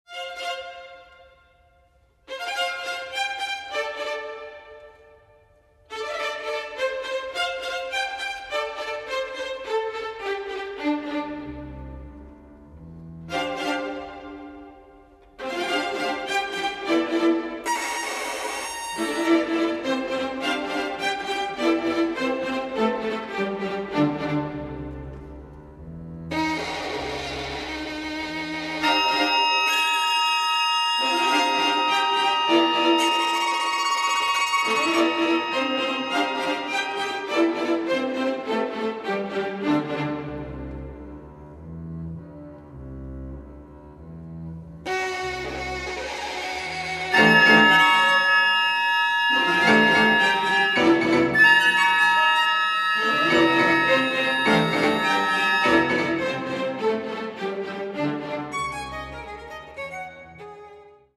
Классическая
для скрипки і камерного оркестру